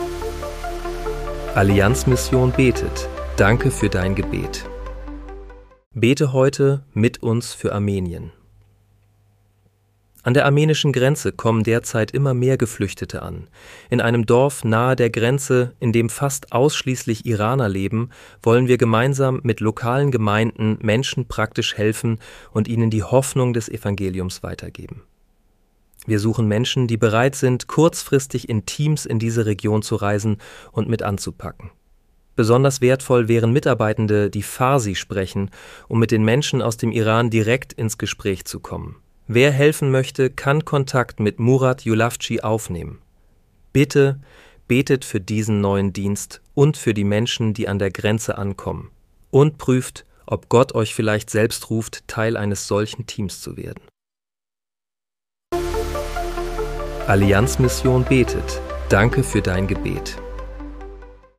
Bete am 10. März 2026 mit uns für Armenien. (KI-generiert mit der